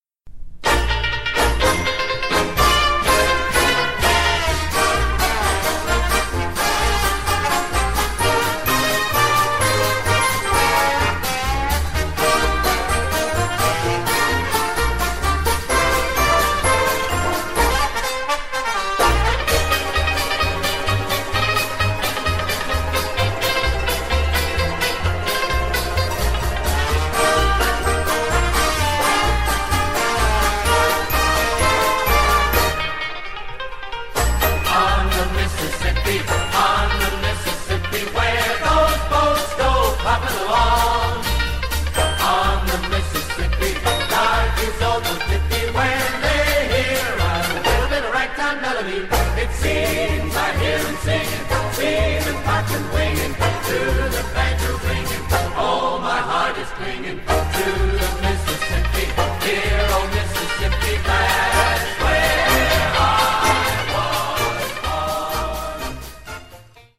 8-beat intro.
Listen to the Big Ben Banjo Band perform "On The Mississippi" (mp3)